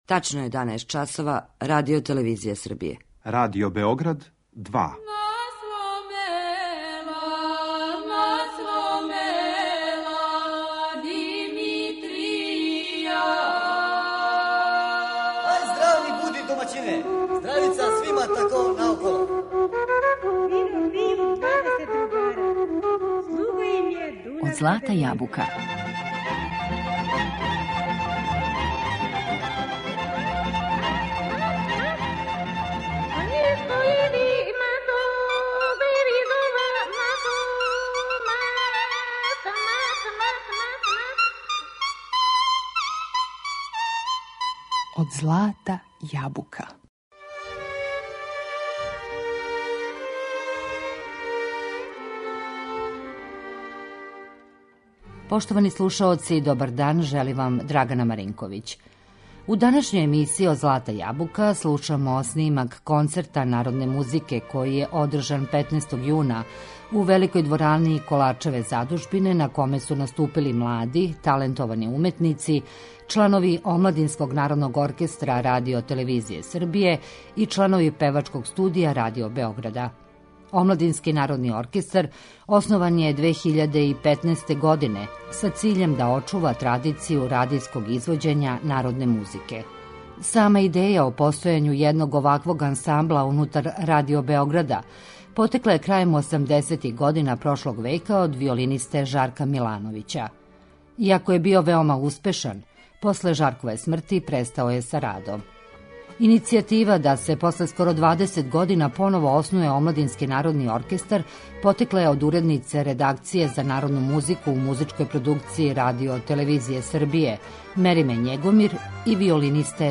Снимак концерта Омладинског народног оркестра РТС-а и Певачког студија Радио Београда
У данашњој емисији слушамо снимак концерта одржаног 15. јуна у Великој сали Коларчеве задужбине, на коме су наступили млади, талентовани уметници, чланови Омладинског народног оркестра РТС-а и чланови Певачког студија Радио Београда.